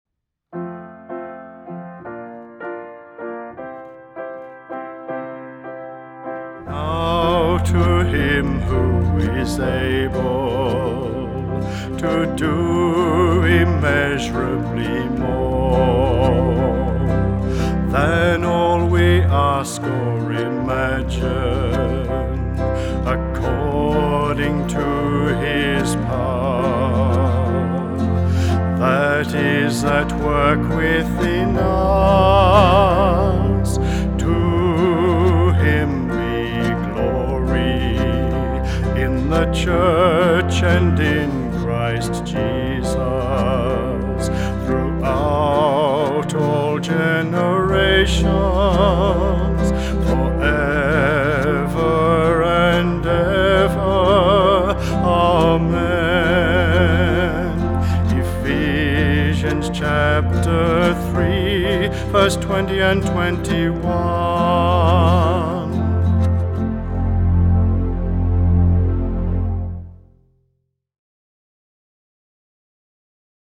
Vocalist
Piano
Keyboard
Guitar